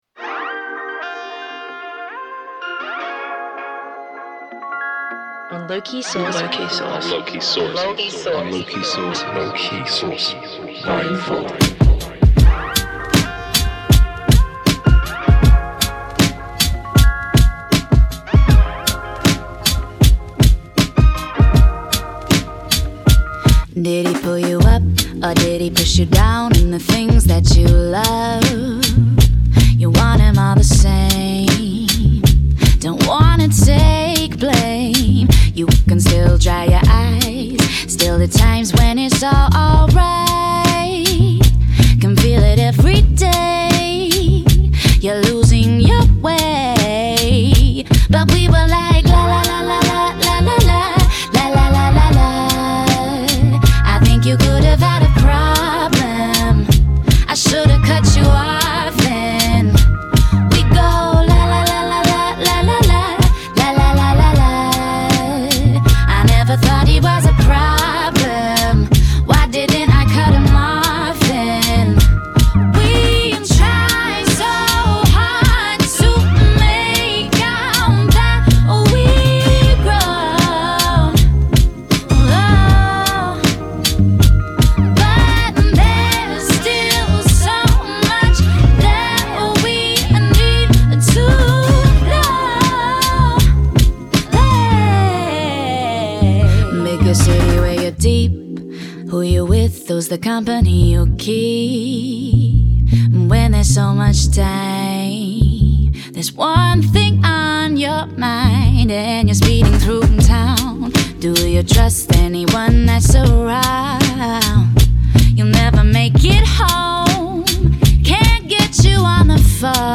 楽曲収録アーティストのシャウトも随所にちりばめられ、シリーズ最高峰作品となっていることをここに断言。